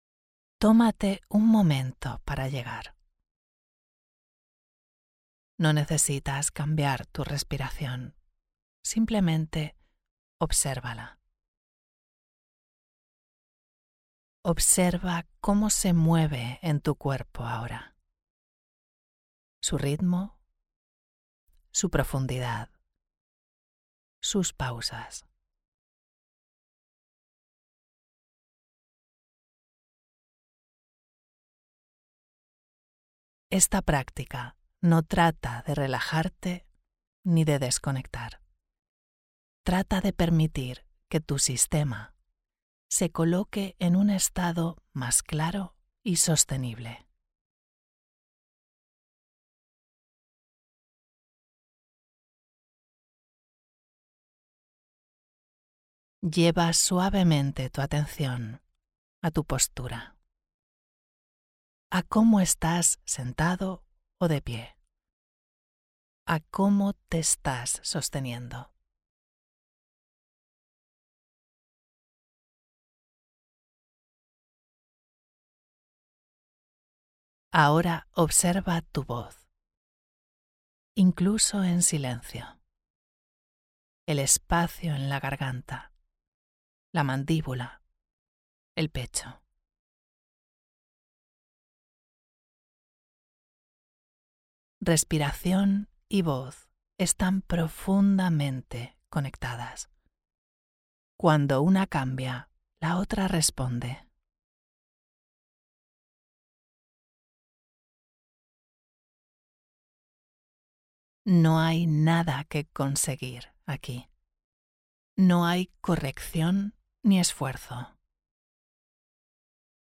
Respira-conmigo-AOP-FBR.mp3